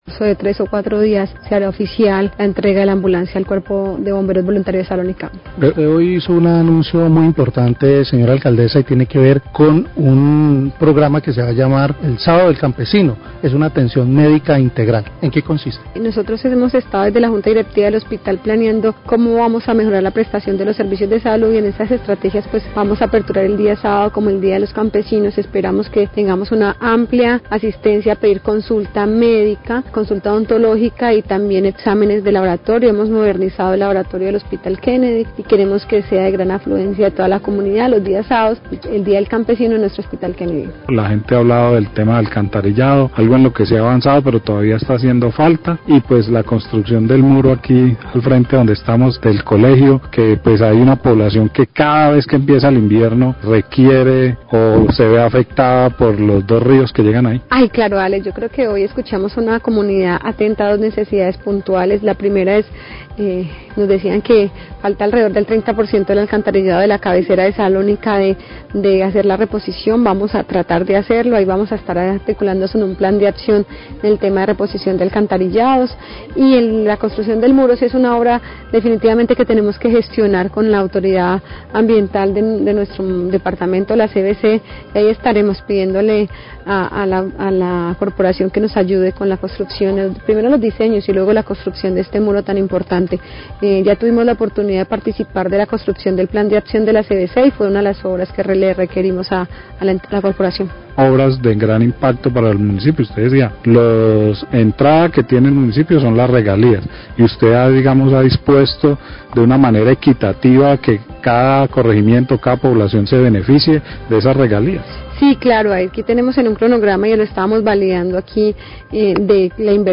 Alcaldesa de Ríofrio habla de solicitud a CVC para construcción de muro